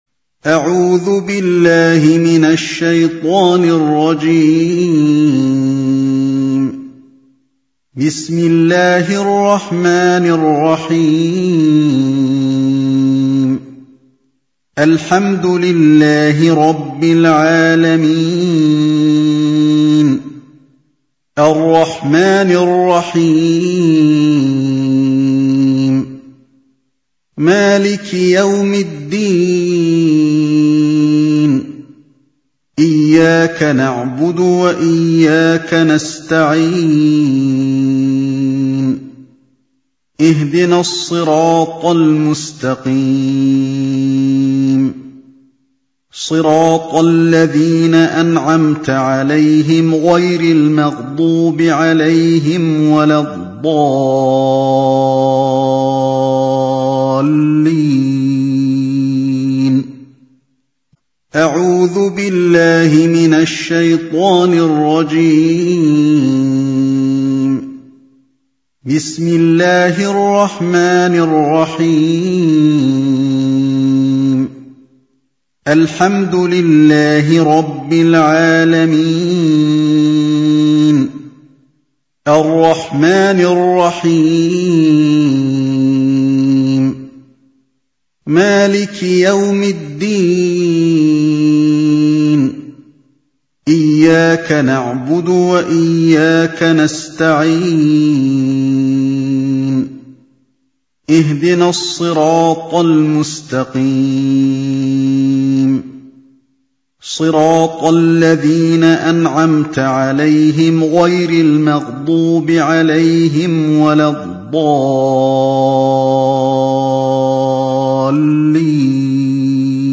الفاتحة القارئ: فضيلة الشيخ علي بن عبد الرحمن الحذيفي الصنف: تلاوات تاريخ: السبت 24 رمضان 1436 هـ الموافق لـ : 11 جويلية 2015 م رواية : حفص عن عاصم الحجم:359.3K المدة :00:01:01 حمله :280 سمعه :1278 سماع التلاوة تحميل التلاوة